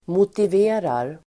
Uttal: [motiv'e:rar]
motiverar.mp3